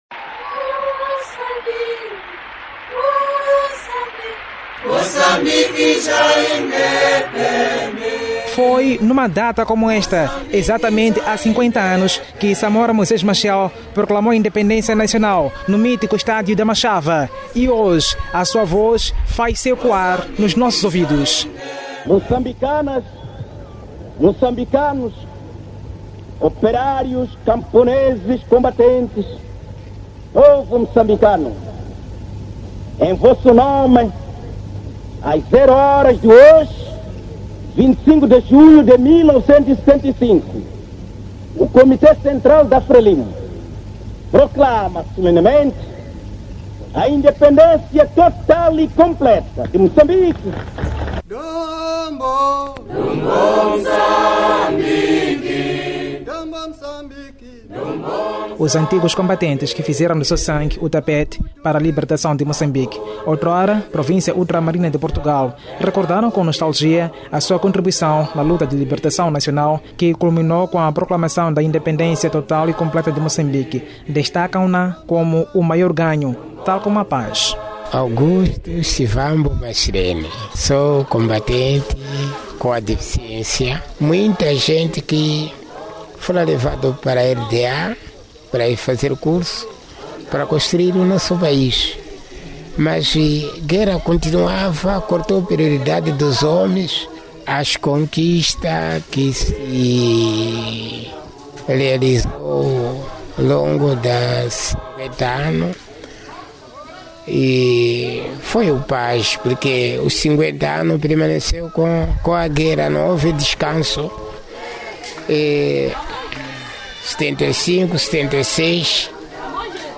ANTIGOS COMBATENTES, CIDADÃOS E HISTORIADORES NA BEIRA DESTACAM AVANÇOS E DESAFIOS DO PAÍS NOS ÚLTIMOS 50 ANOS
ESPECIAL-REPORTAGEM-5O-ANOS-DE-INDEPENDENCIA-.mp3